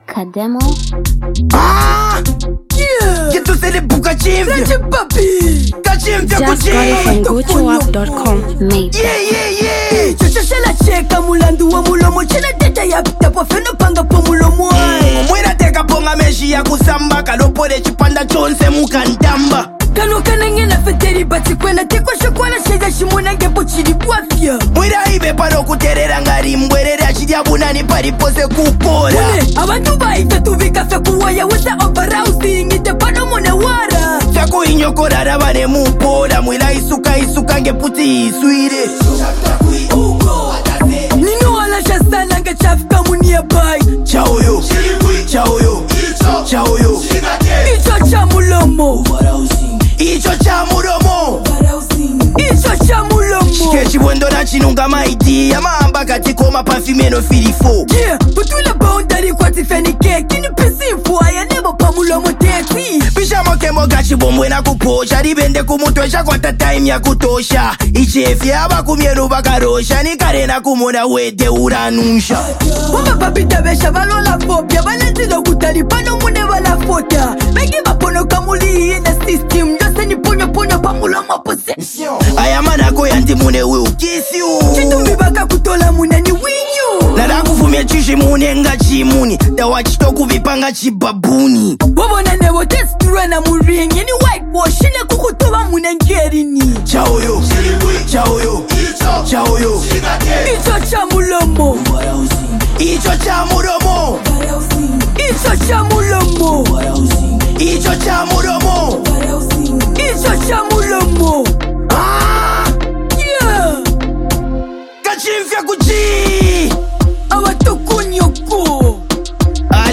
Zambian Mp3 Music
Copperbelt renowned Up talented duo hardcore rappers